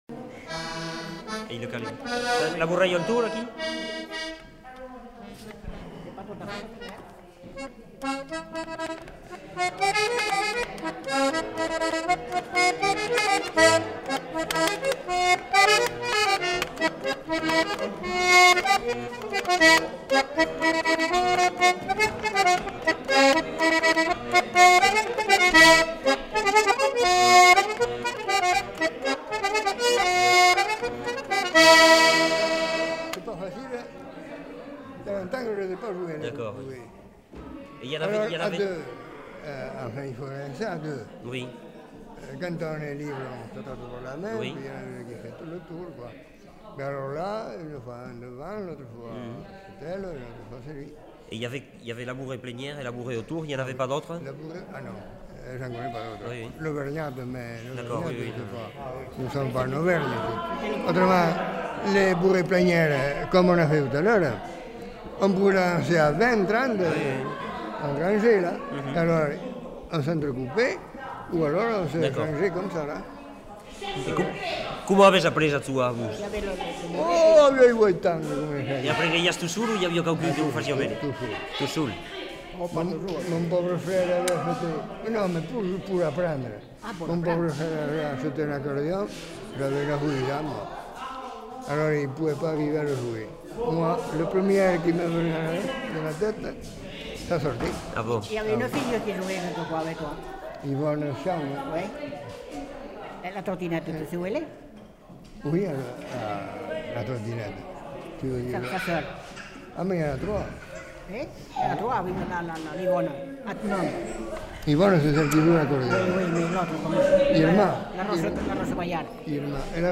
Lieu : Lauzun
Genre : morceau instrumental
Instrument de musique : accordéon diatonique
Danse : bourrée
Notes consultables : Précisions sur la danse en fin de séquence.